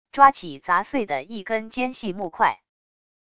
抓起砸碎的一根尖细木块wav音频